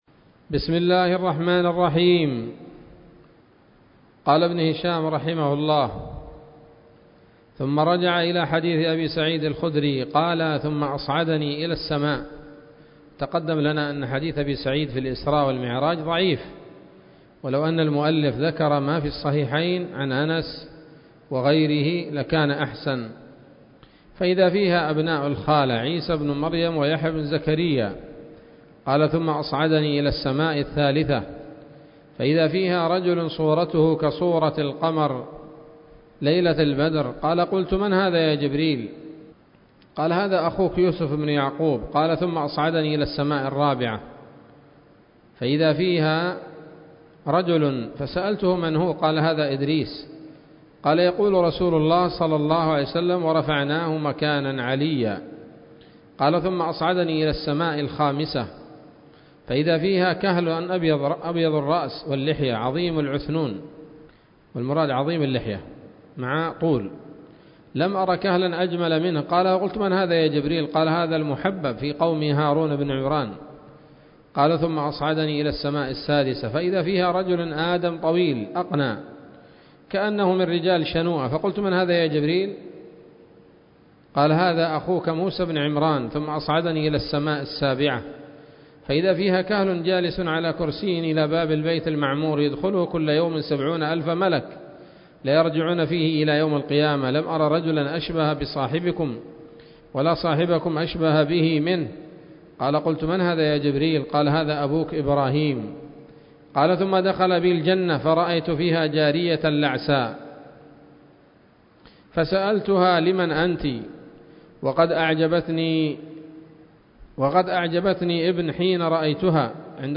الدرس الحادي والخمسون من التعليق على كتاب السيرة النبوية لابن هشام